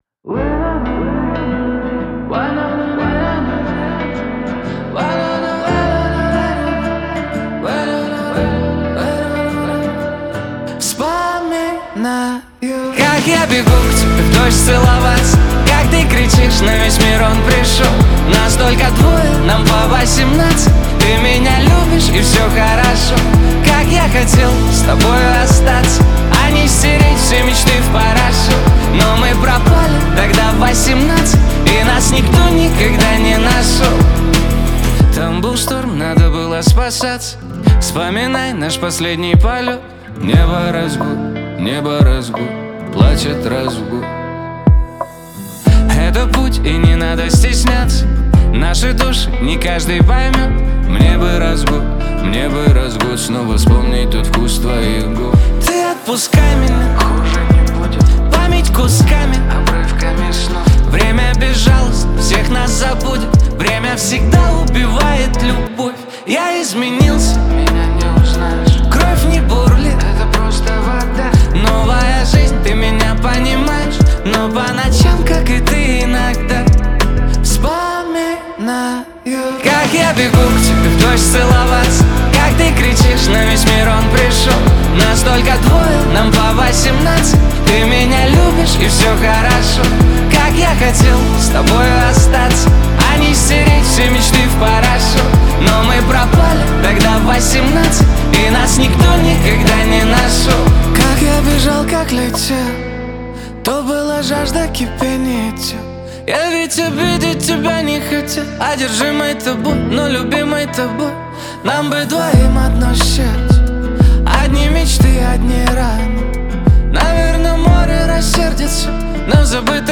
Душевная песня